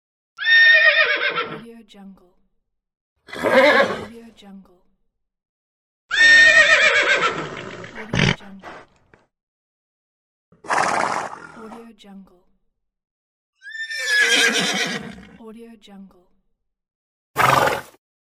Horse Neigh Bouton sonore
The Horse Neigh sound button is a popular audio clip perfect for your soundboard, content creation, and entertainment.